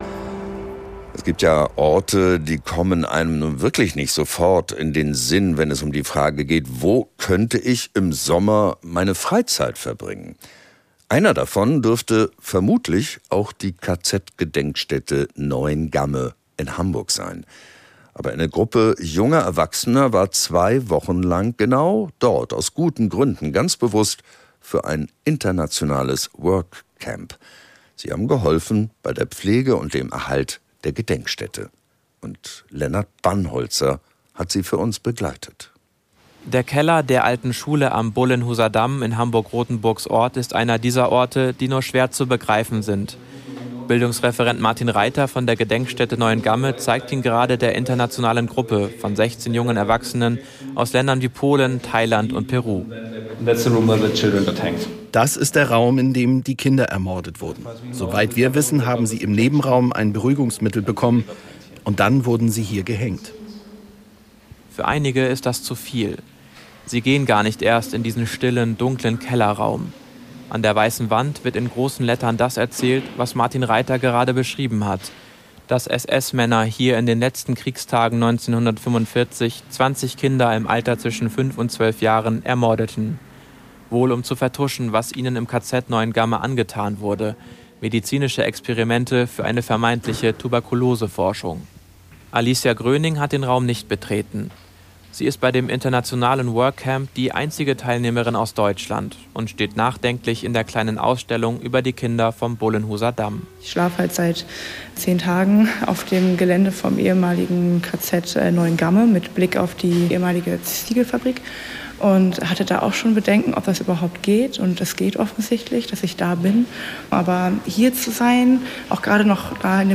- Radiobeitrag "Workcamp - Erinnerung erhalten in KZ-Gedenkstätte Neuengamme (2024) beim NDR oder als